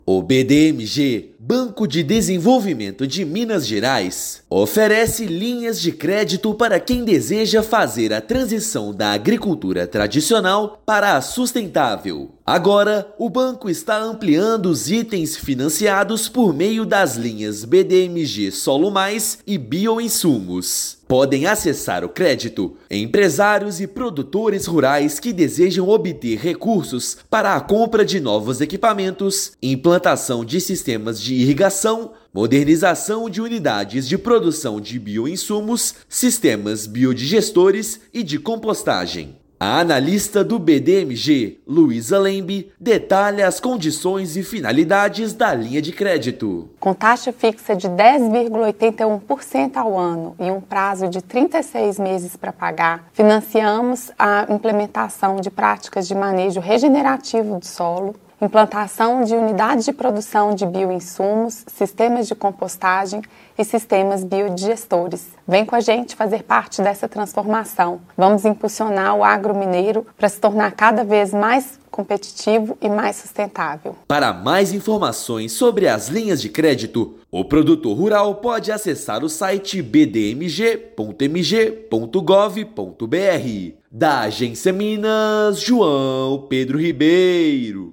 No mês do Meio Ambiente, banco anuncia ampliação da forma de atuar no setor por meio de crédito para a compra de equipamentos e afins. Ouça matéria de rádio.